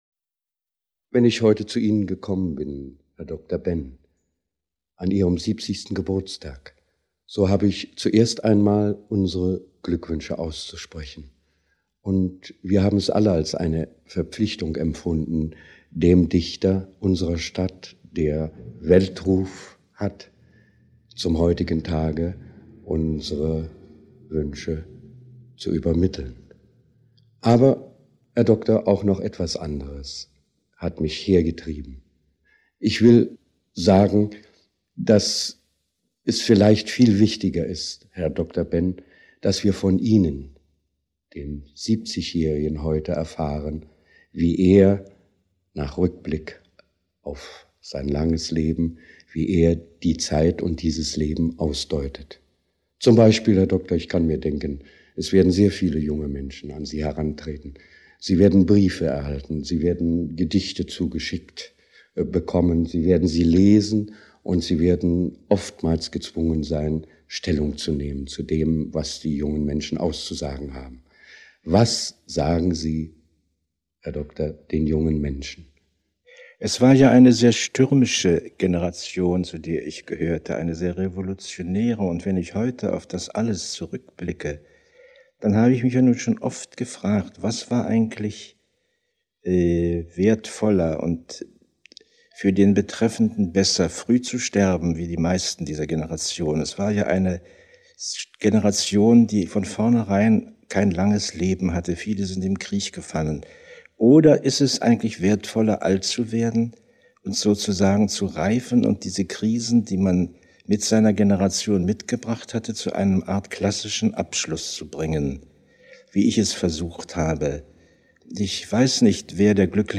Zum 70. Geburtstag. Rundfunkinterview